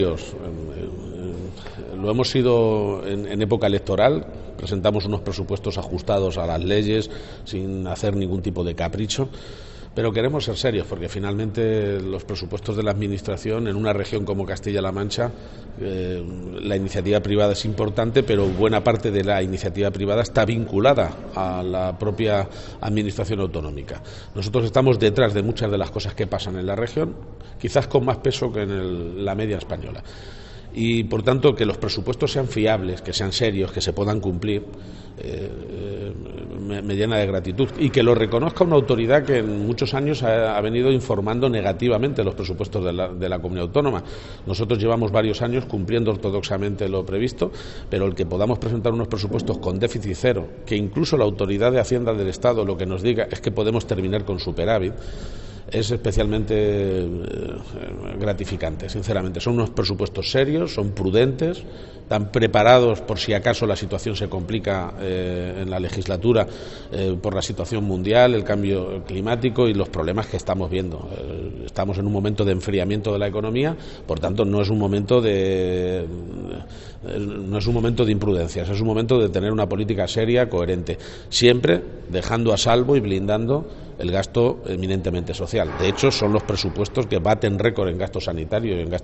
declaraciones_garciapage_en_toulouse_presupuesto.mp3